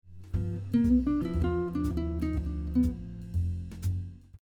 Lick 3 incorporates a triplet on beat two and also uses chromatic notes.
Blues lick highlighting chord tones